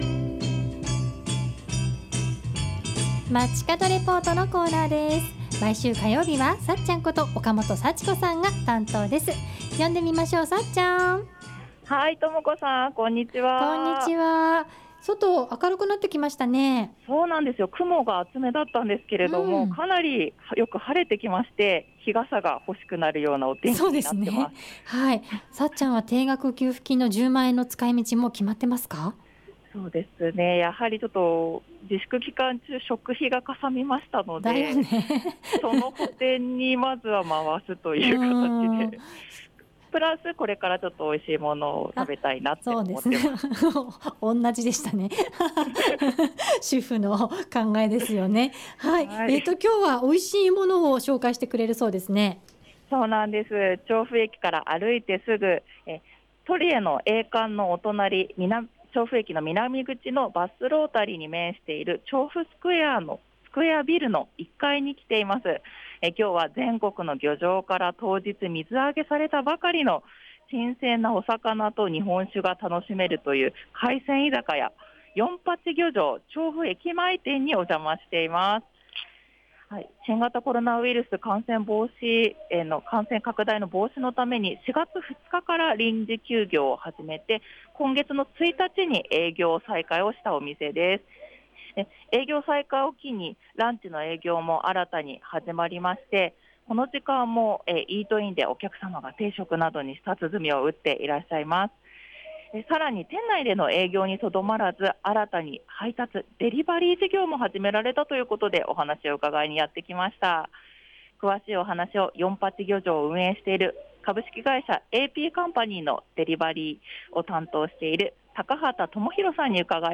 街角レポート
中継は、全国の漁場から当日水揚げされたばかりの新鮮な魚と日本酒が楽しめる海鮮居酒屋 「四十八漁場（よんぱちぎょじょう） 調布駅前店」にお邪魔しました!